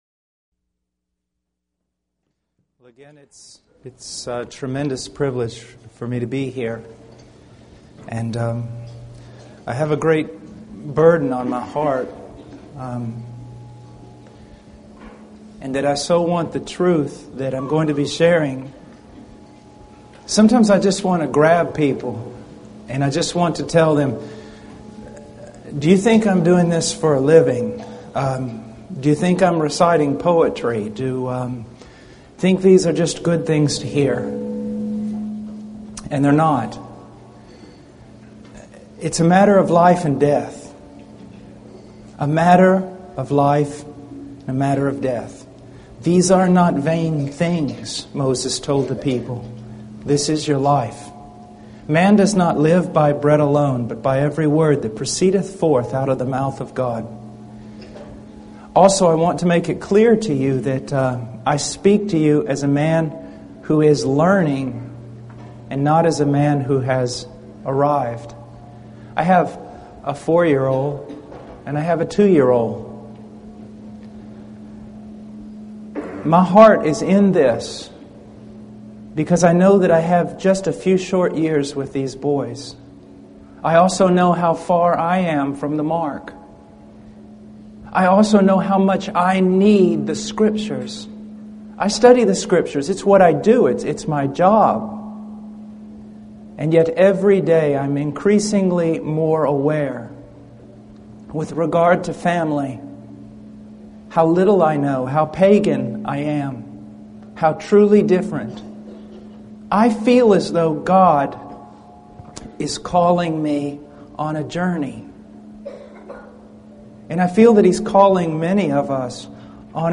In this sermon, the speaker expresses his deep concern for the upbringing of his young children and acknowledges his own shortcomings. He emphasizes the importance of studying and following the scriptures in order to lead a godly family life.